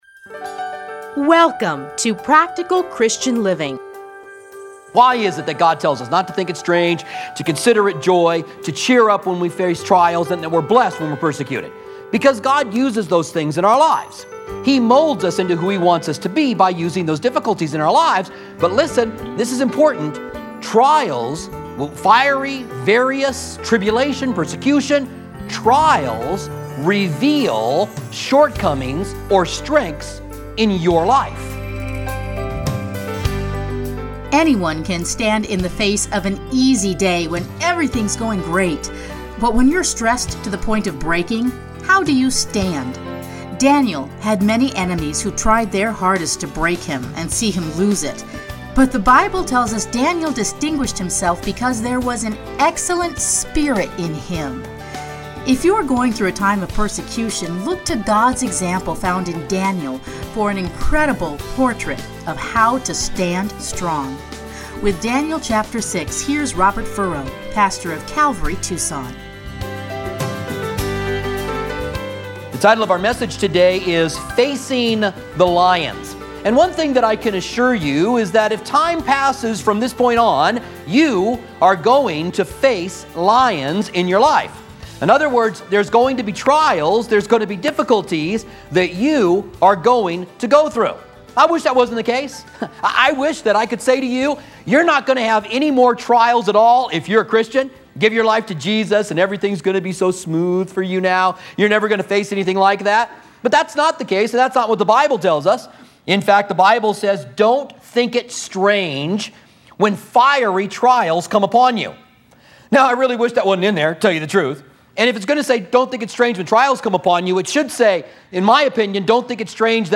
Listen here to his series in Daniel.